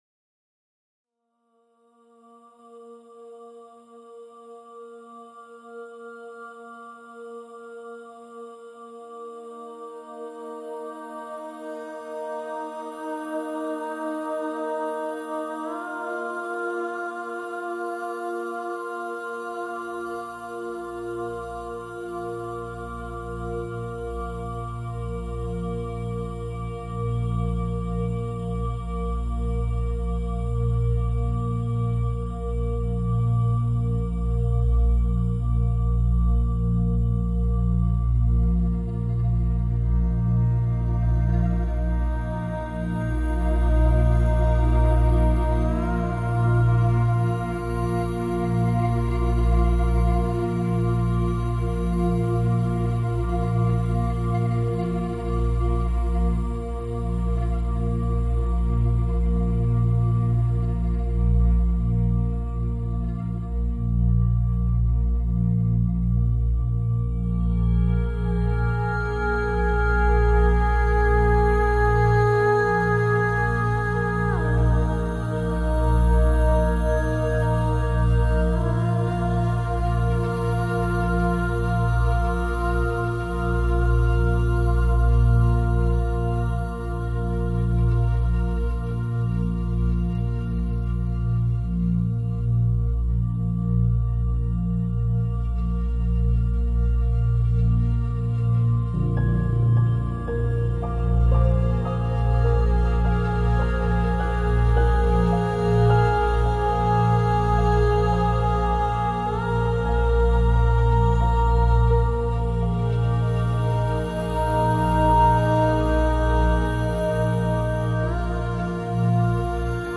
girl voice and pads